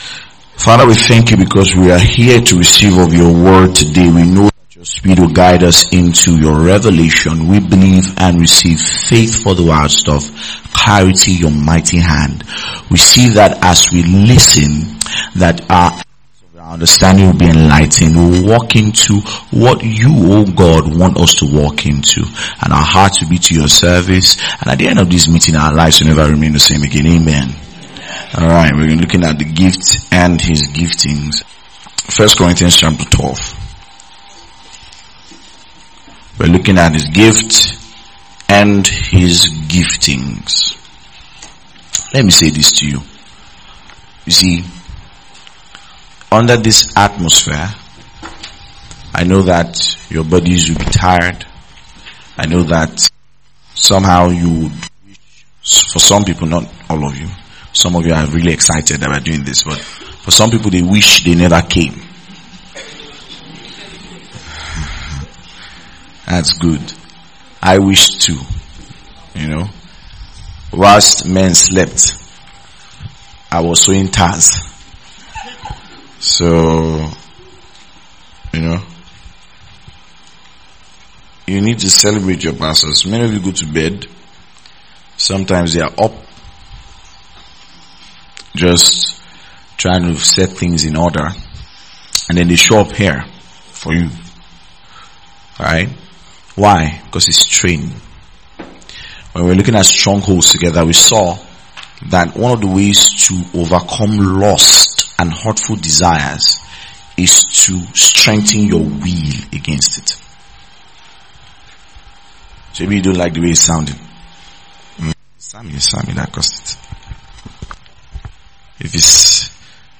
2025 Recalibrate 2025 download Download Sermon MP3 home Home play_circle Sermons article Blog church Nexuses